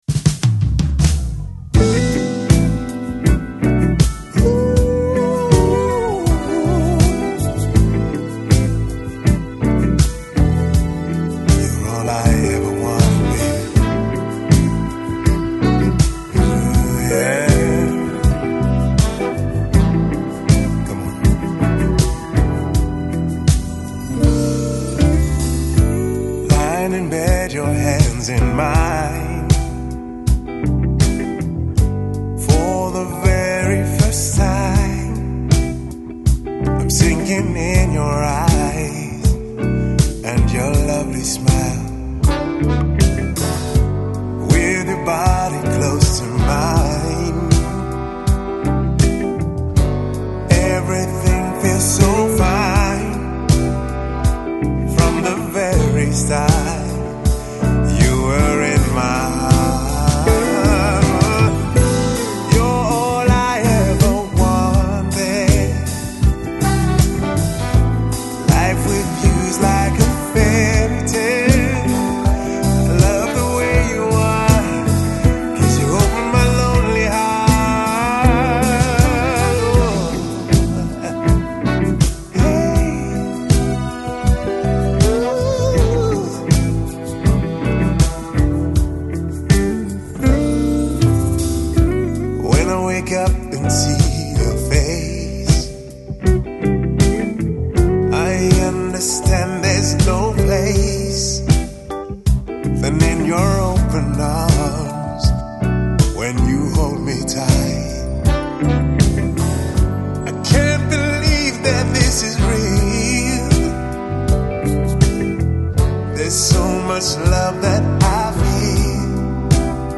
Жанр: Downtempo, Lounge, Soul